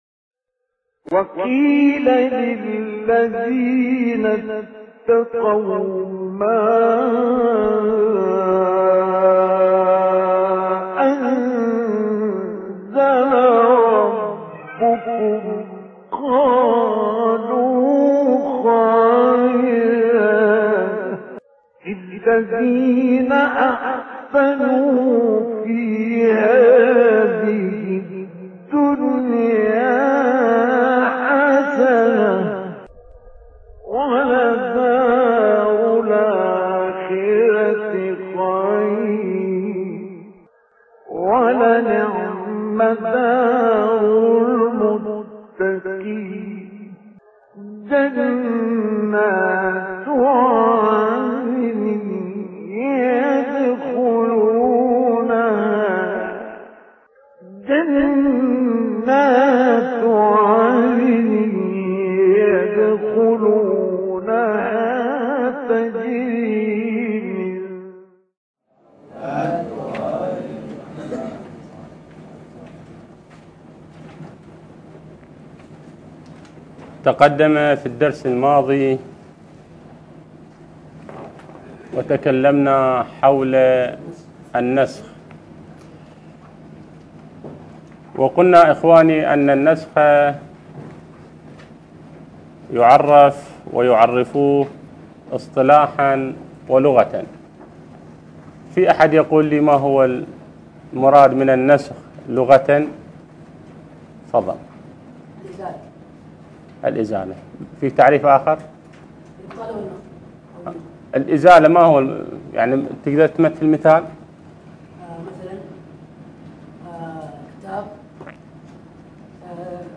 الدرس الخامس صيانة القرآن من التحريف - لحفظ الملف في مجلد خاص اضغط بالزر الأيمن هنا ثم اختر (حفظ الهدف باسم - Save Target As) واختر المكان المناسب